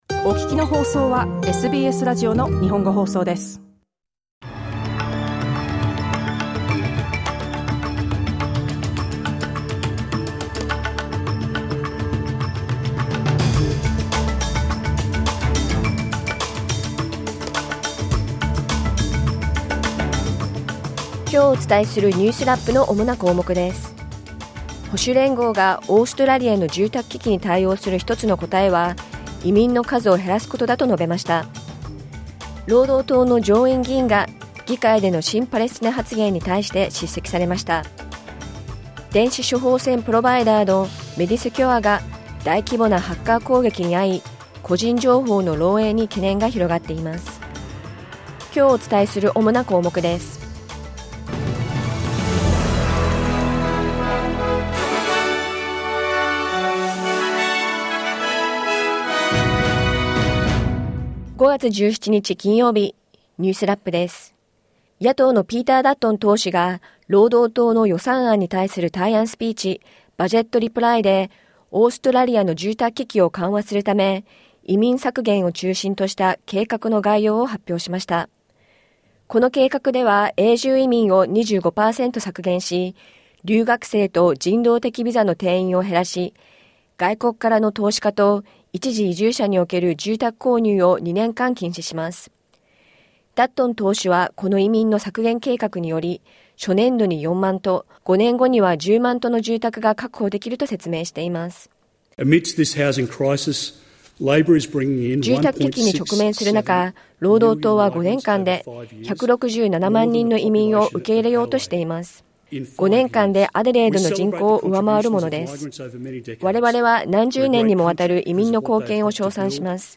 SBS日本語放送週間ニュースラップ 5月17日金曜日